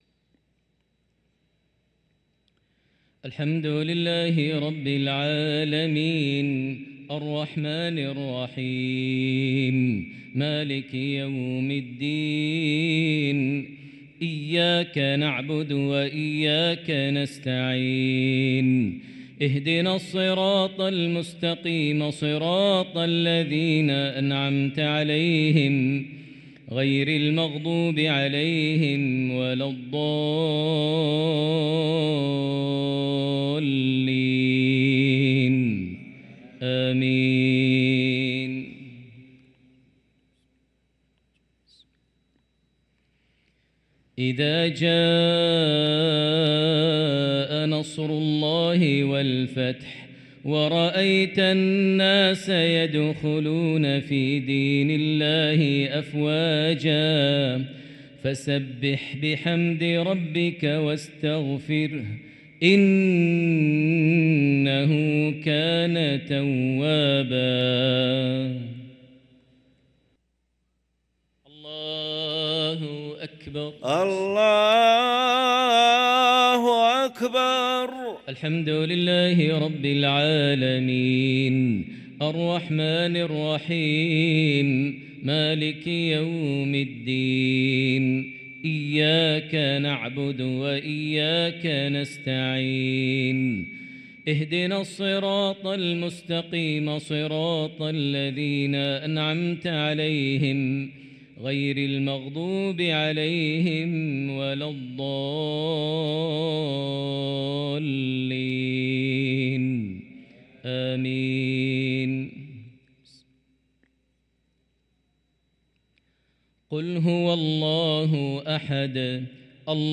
صلاة المغرب للقارئ ماهر المعيقلي 5 صفر 1445 هـ
تِلَاوَات الْحَرَمَيْن .